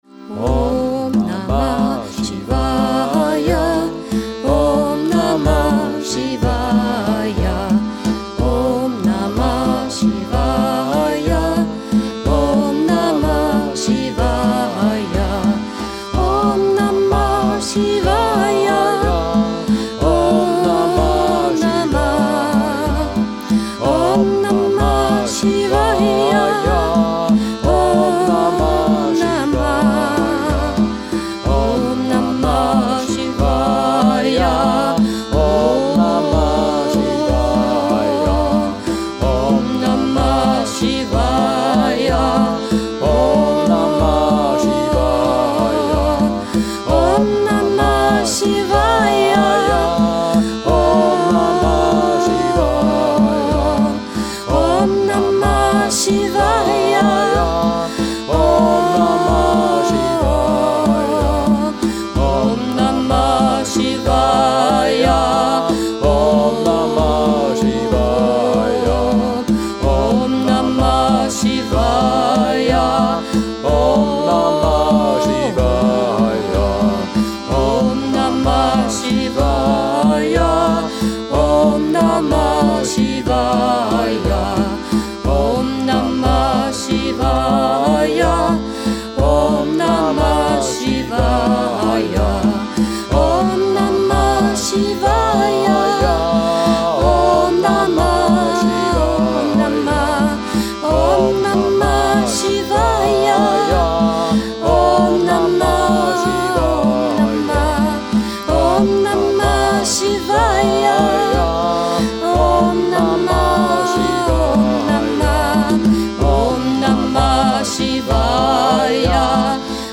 Nachdem wir zuerst im 4/4 Takt probiert hatten kam die Idee zum 3/4 Takt und das Ergebnis hat uns begeistert.